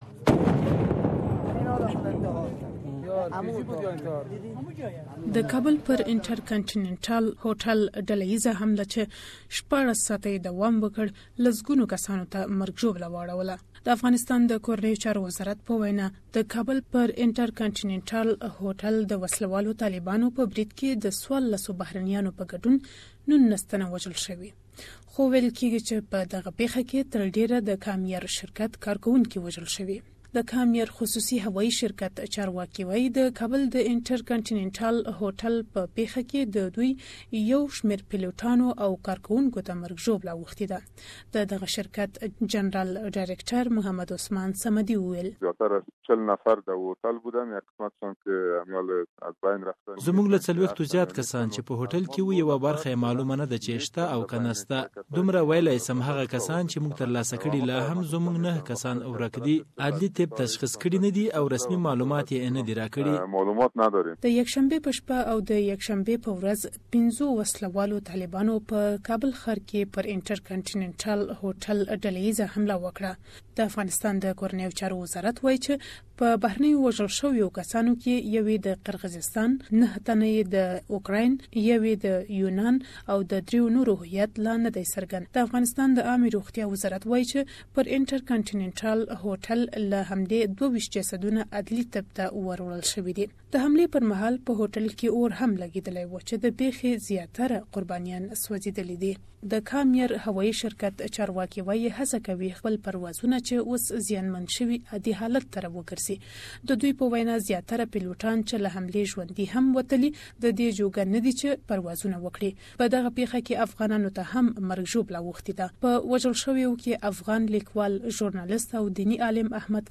SBS Pashto